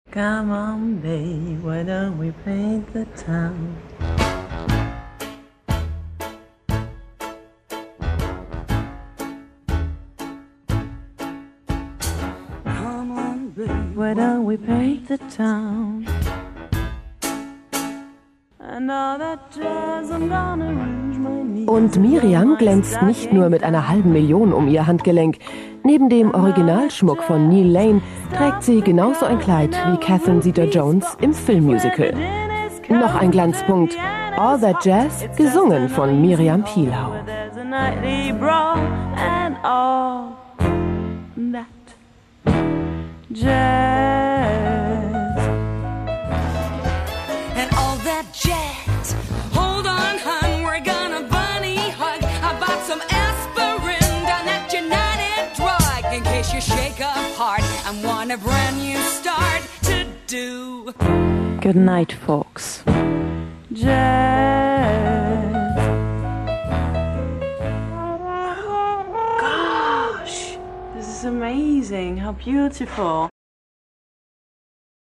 TV-Beitrag